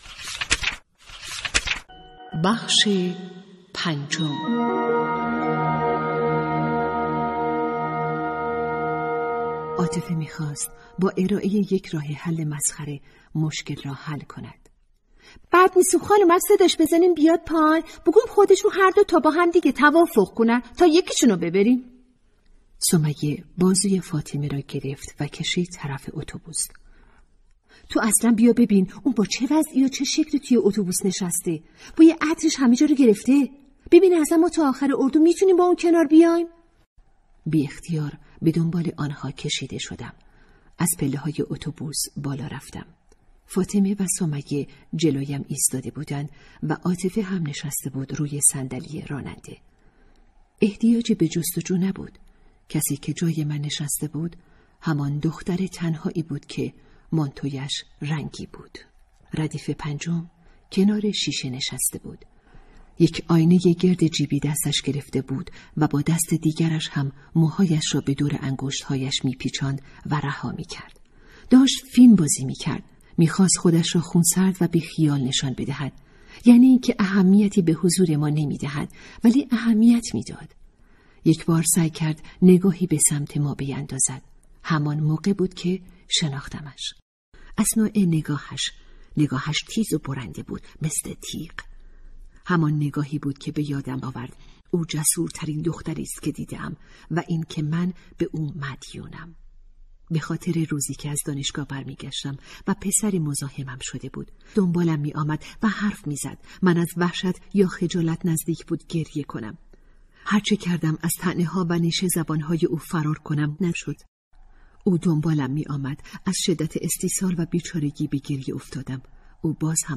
کتاب صوتی | دختران آفتاب (05)